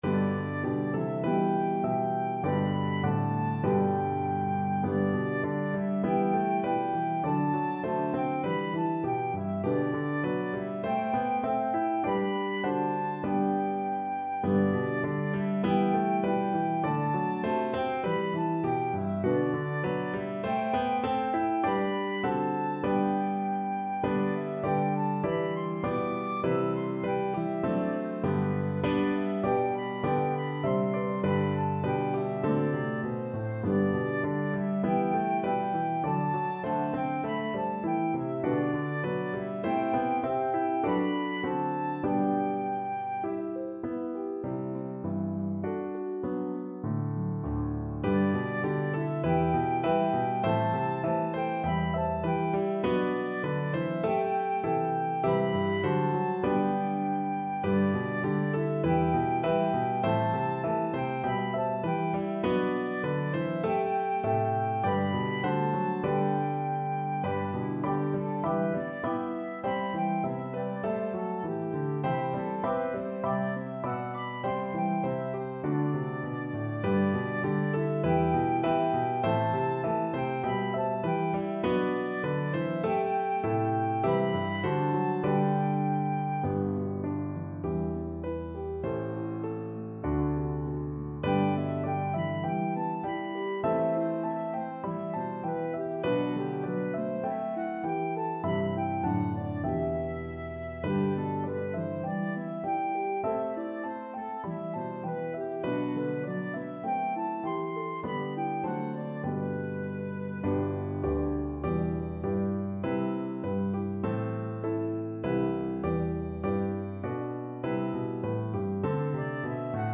a pentatonic melody